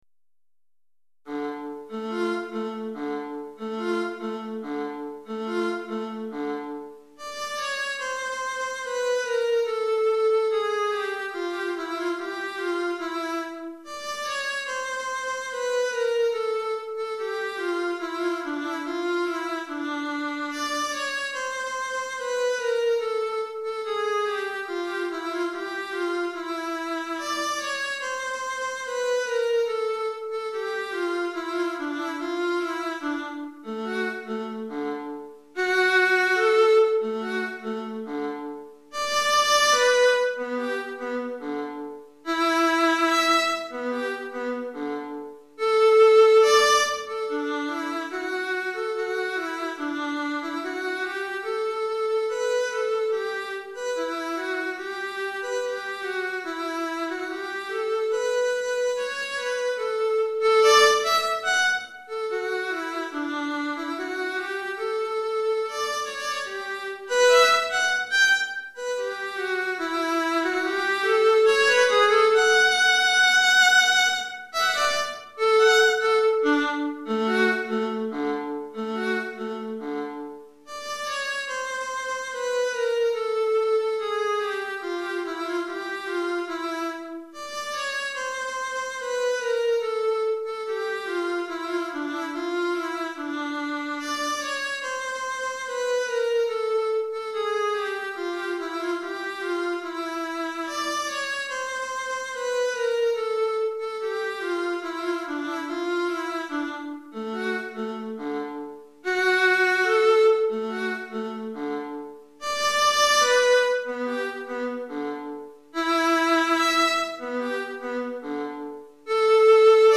Alto Solo